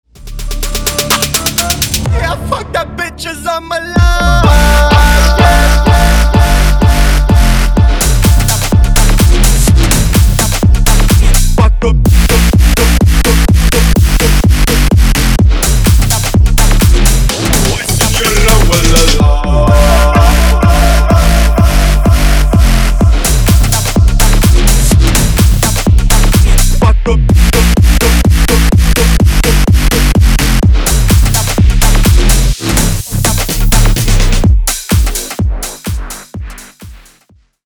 Ремикс # Рэп и Хип Хоп # клубные
громкие